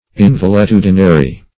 Invaletudinary \In*val`e*tu"di*na*ry\, a. Wanting health; valetudinary.